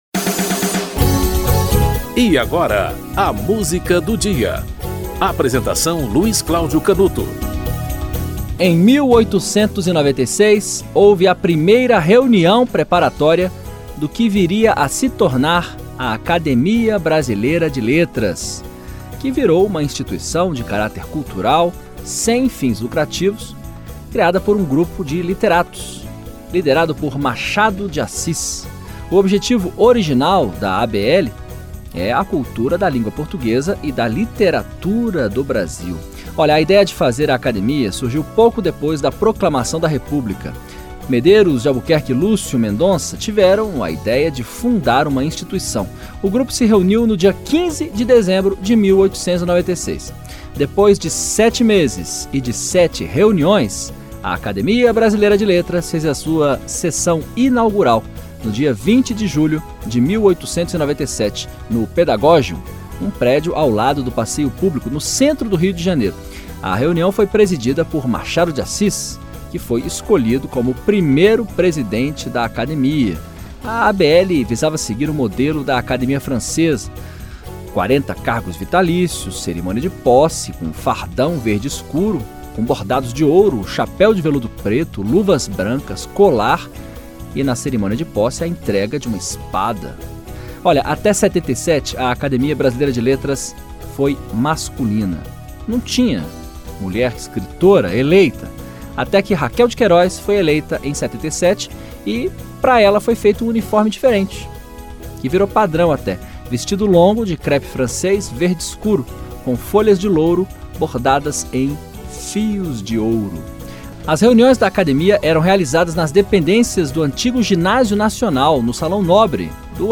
Produção e apresentação-